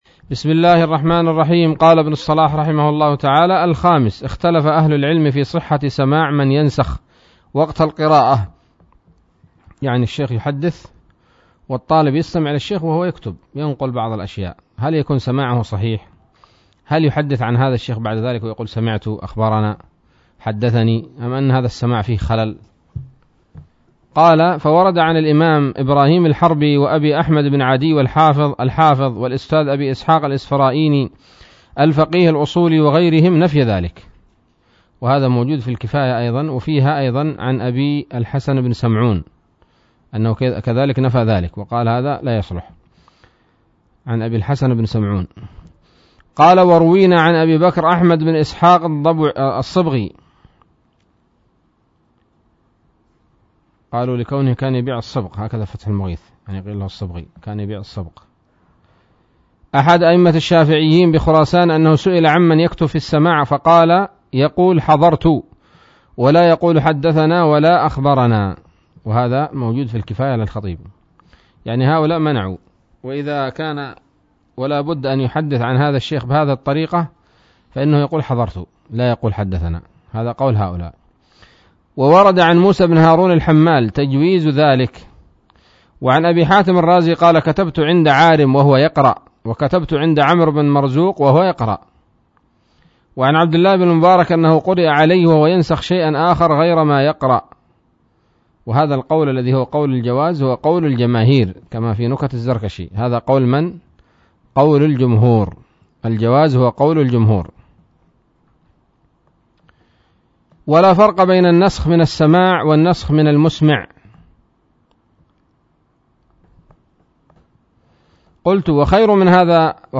الدرس الرابع والستون من مقدمة ابن الصلاح رحمه الله تعالى